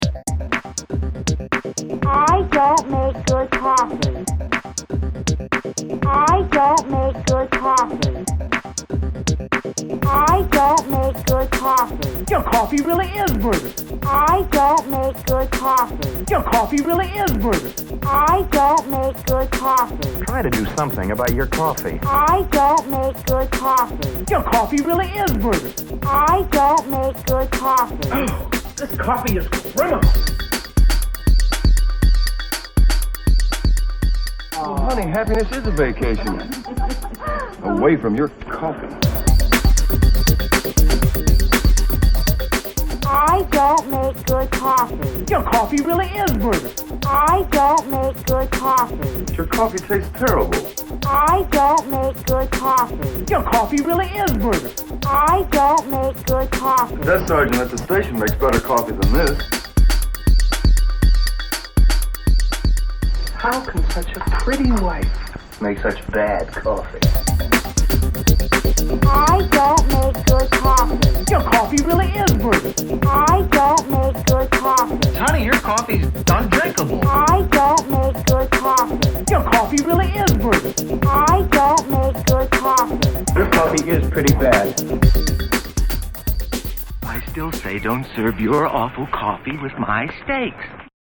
Yet another attempt at sound samples in a song.